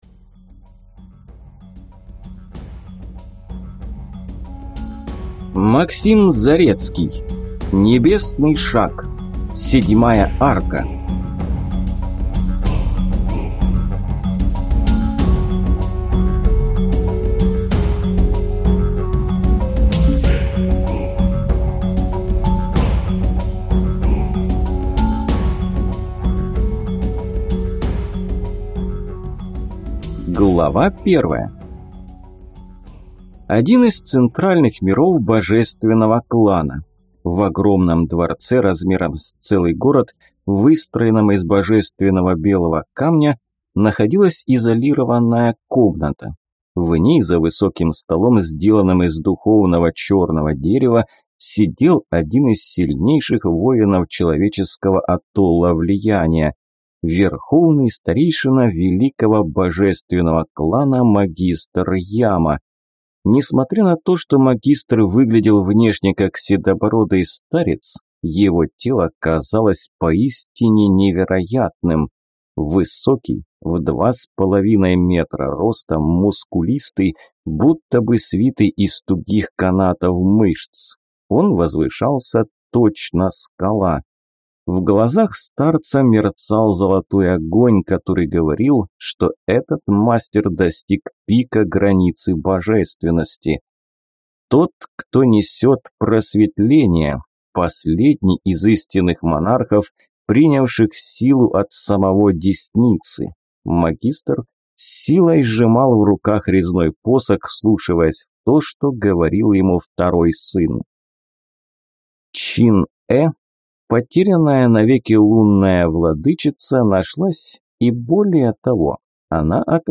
Аудиокнига Небесный шаг (7 арка) | Библиотека аудиокниг